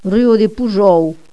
Prononcer Poujòw, Pouyòw...